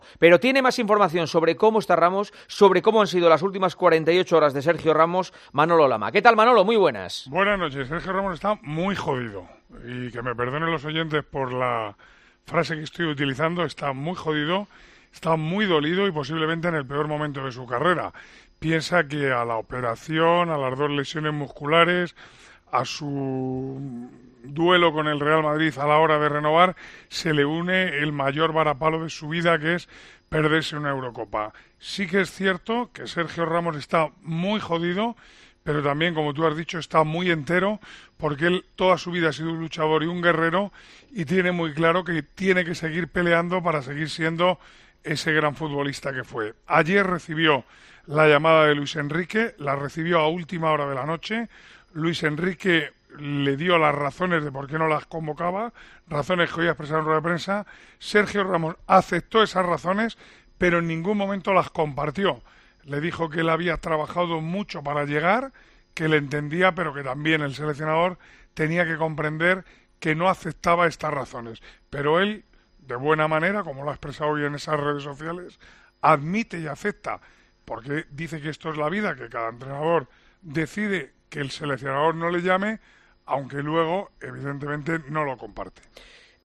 Informa Manolo Lama